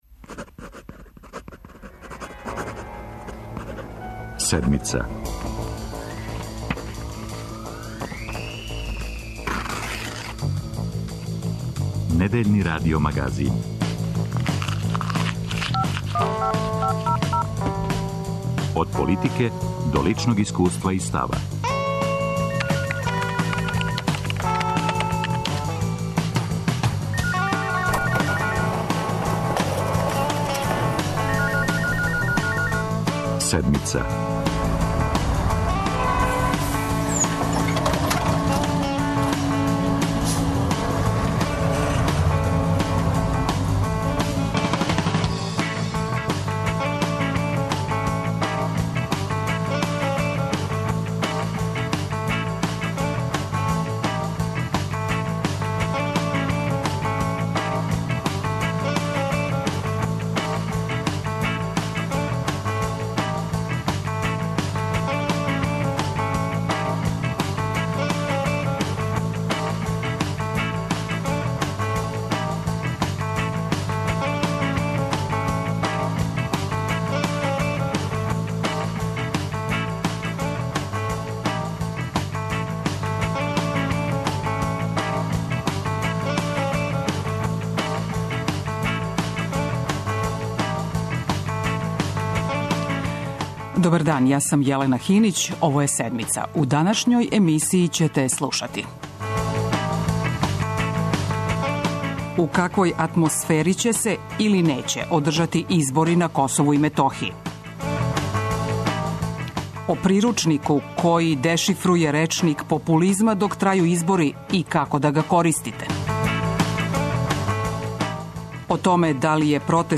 Репортерска екипа била је у Љубичевцу.